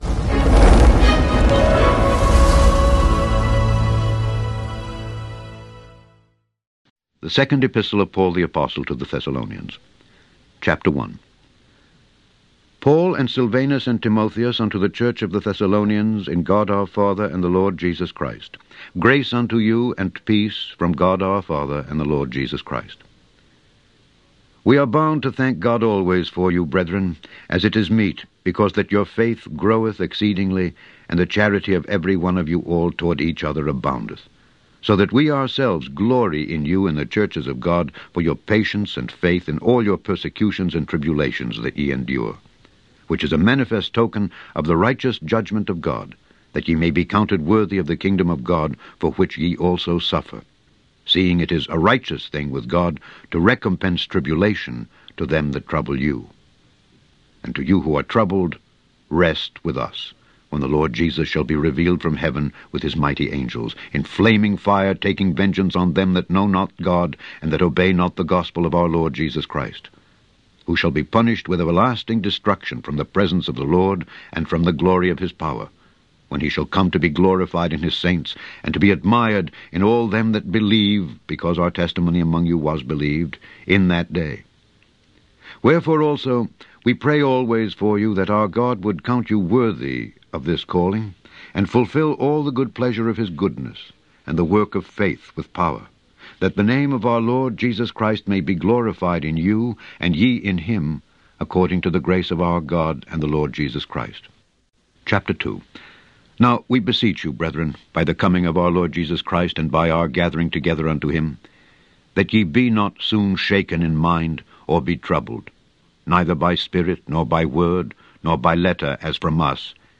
In this podcast, you can listen to Alexander Scourby read the book of 2 Thessalonians.